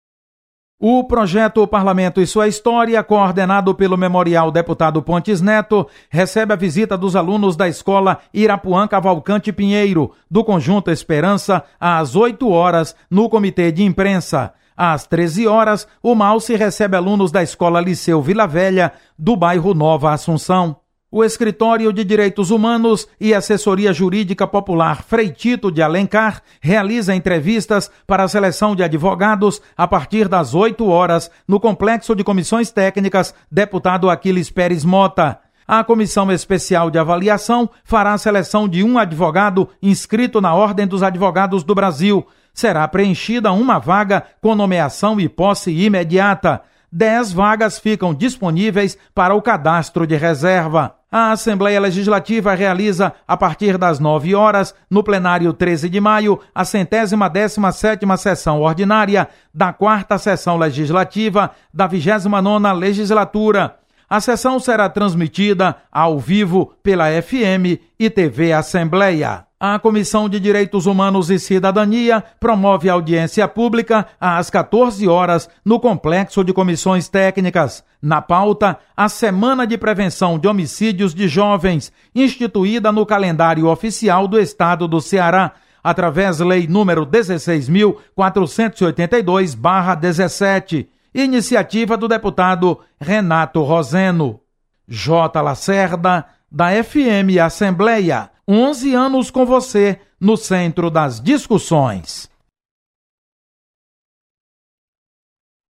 Acompanhe as atividades da Assembleia Legislativa nesta terça-feira. Repórter